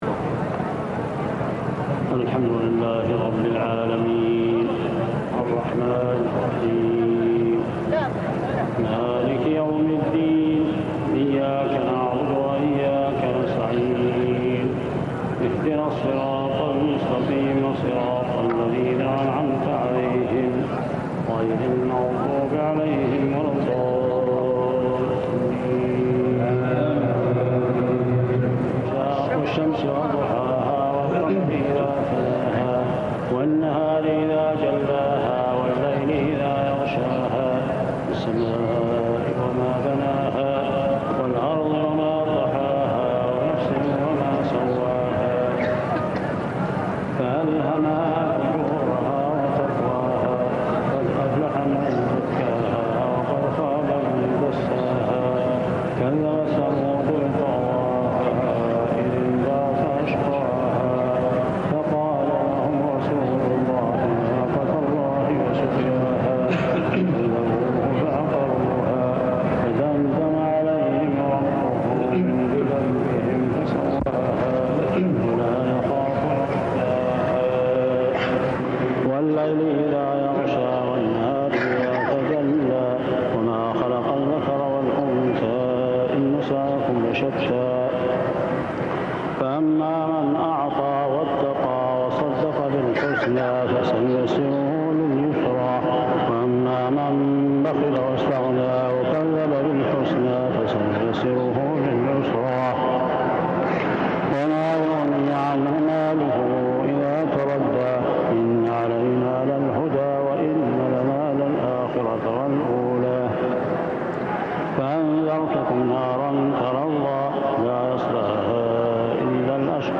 صلاة التراويح ليلة الختم عام 1398هـ من سورة الشمس كاملة حتى سورة المسد كاملة | Tarawih prayer from Surah Ash-Shams to Al-Masad > تراويح الحرم المكي عام 1398 🕋 > التراويح - تلاوات الحرمين